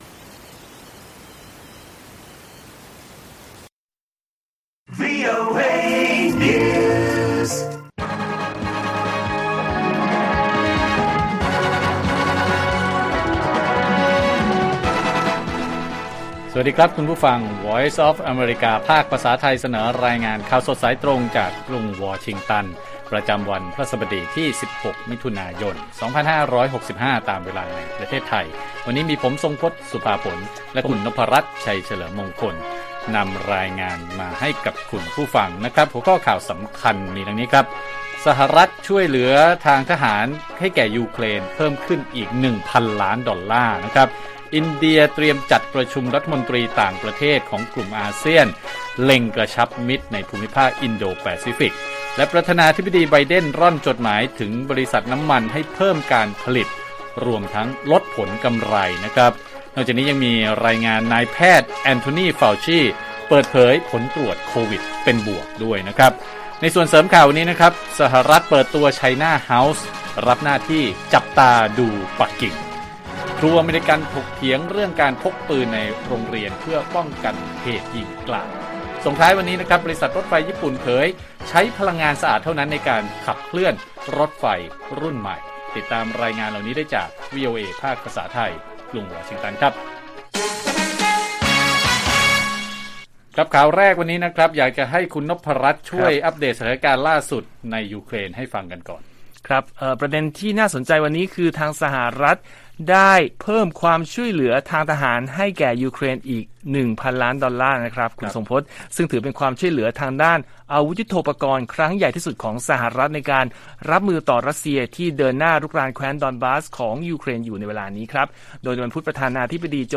ข่าวสดสายตรงจากวีโอเอ ไทย พฤหัสฯ 16 มิ.ย.2565